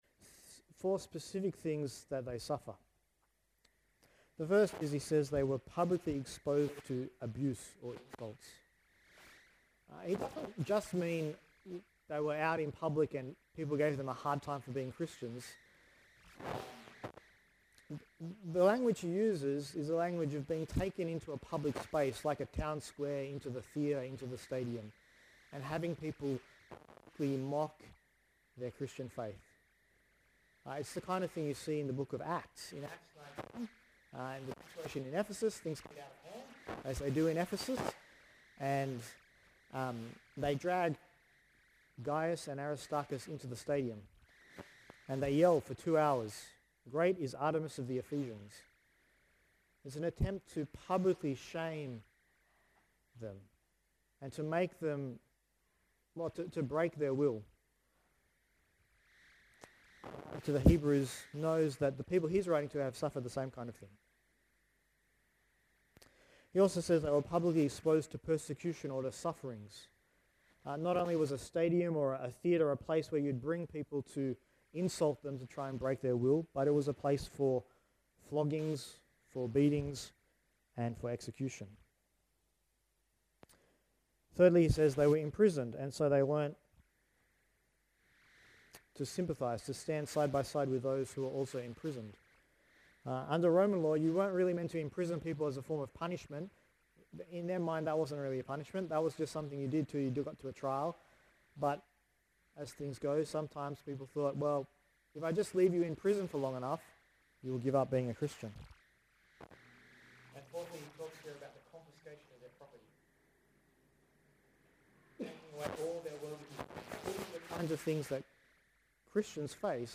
Passage: Revelation 19:1-21 Service Type: WPC Camp 2015